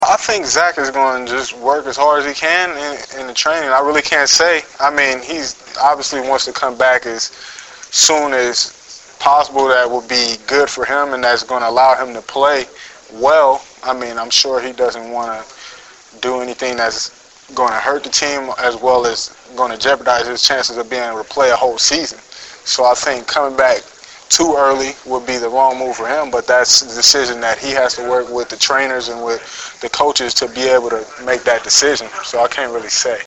Preseason Press Conference
Memorial Stadium - Lincoln, Neb.